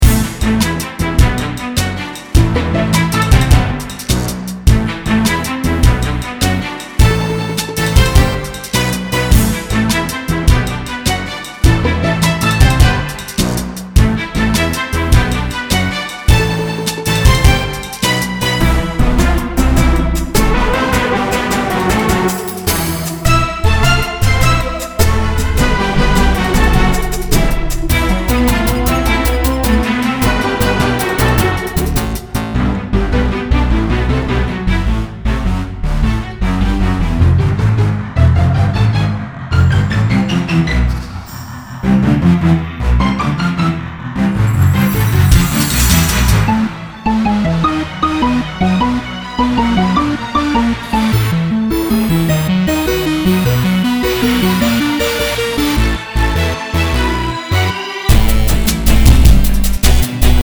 A medeival instruments set that reminds me a bit of an orc theme in a war vs humans type game.